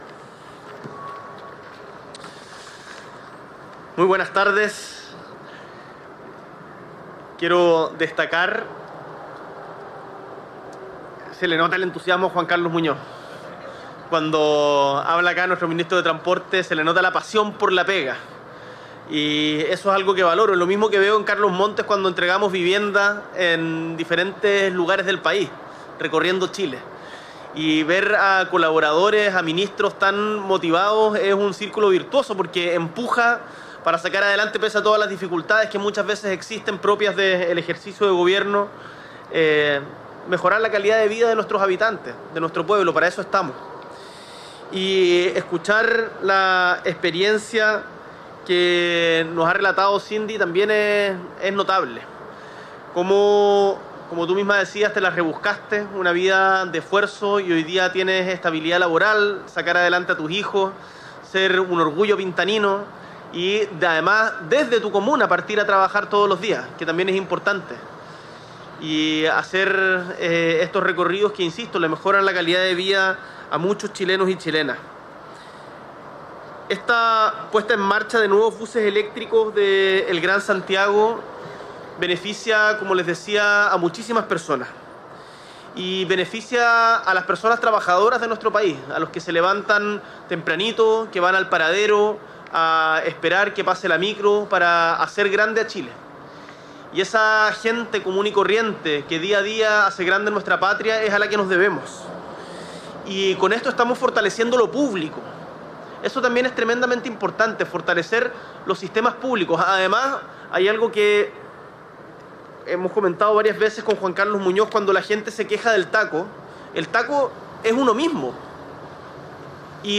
S.E. el Presidente de la República, Gabriel Boric Font, encabeza la puesta en marcha de más de 300 nuevos buses eléctricos en Santiago